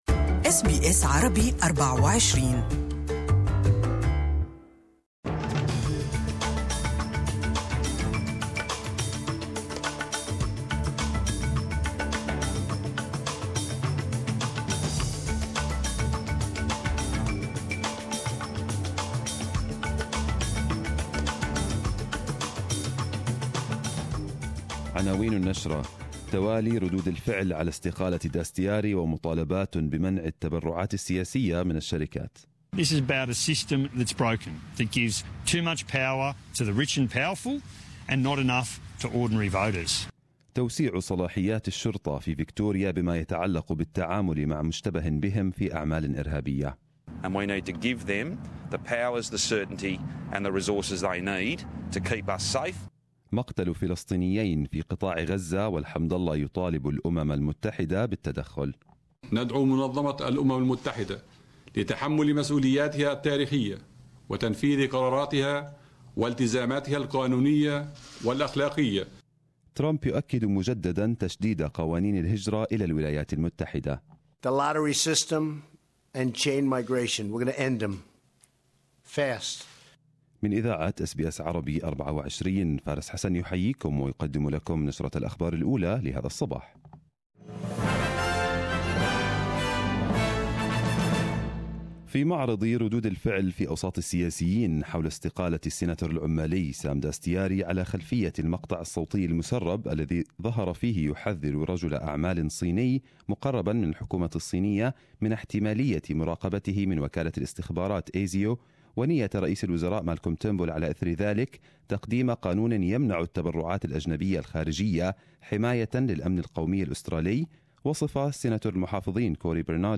Arabic News Bulletin 13/12/2017